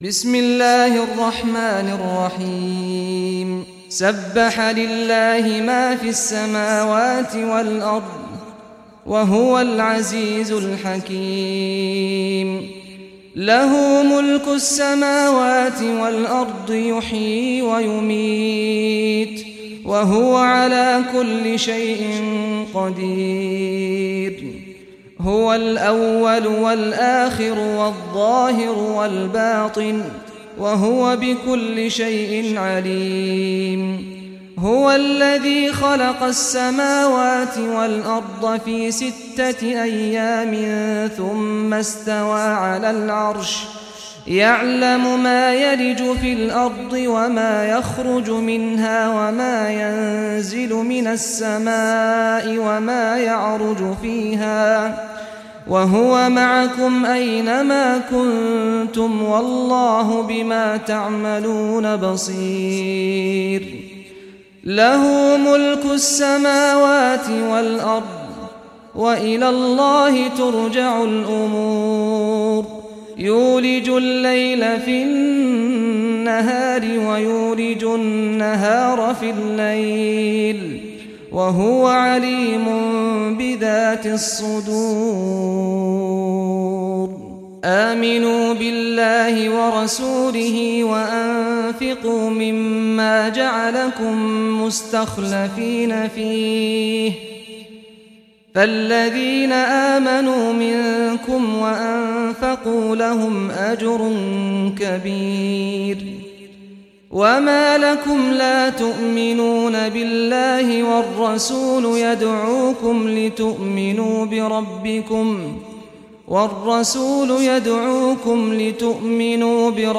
Surah Al-Hadid Recitation by Sheikh Saad al Ghamdi
Surah Al-Hadid, listen or play online mp3 tilawat / recitation in Arabic in the beautiful voice of Imam Sheikh Saad al Ghamdi.